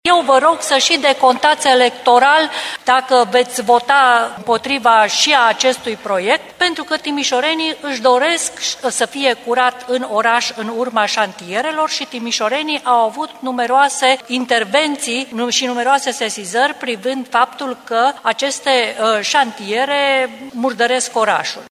Primarul Dominic Fritz nu a dorit retragerea proiectelor, iar după respingerea lor, consilierul USR, Rodica Militaru, le-a cerut liberalilor să răspundă ei în fața timișorenilor.